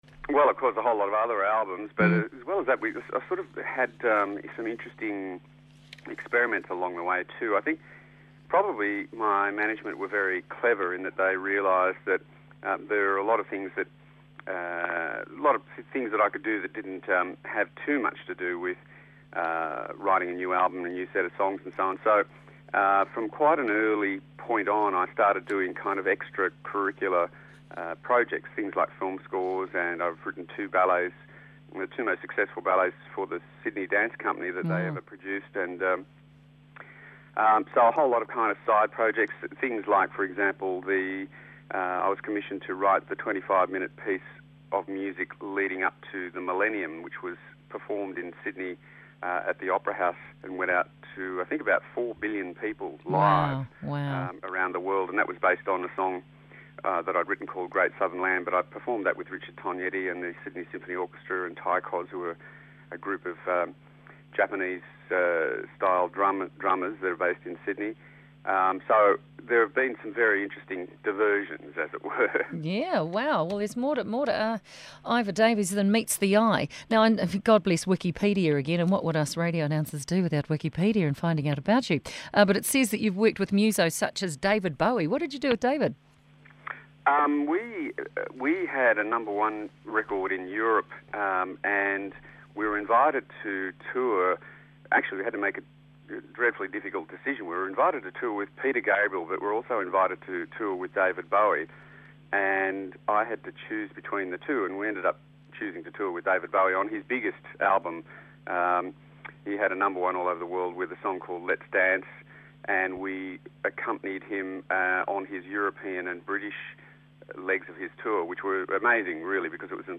Musician Iva Davies talks about his early days in the music industry with band Icehouse and touring with David bowie.